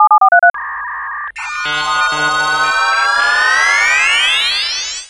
Modem Operation.wav